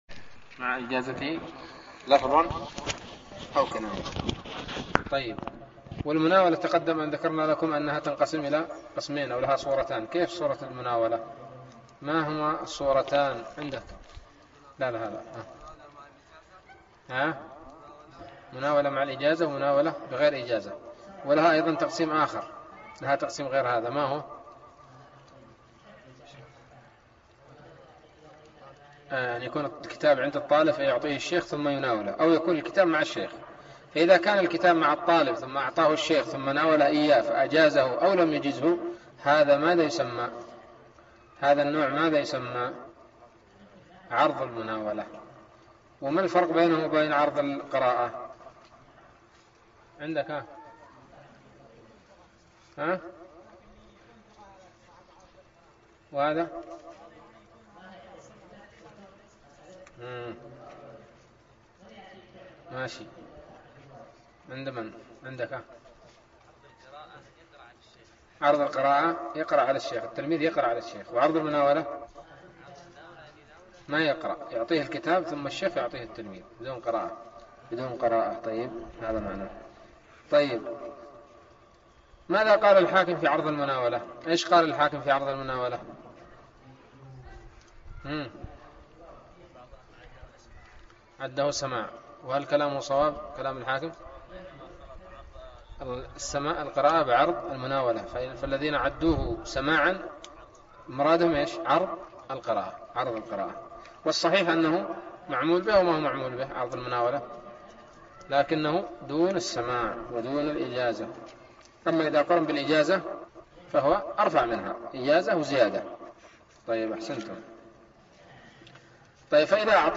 الدرس الأربعون من الباعث الحثيث